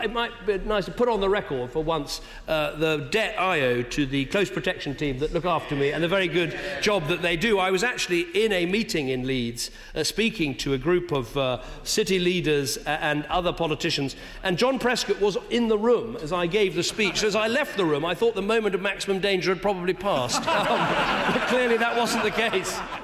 Cameron in the Commons making light of incident in Leeds......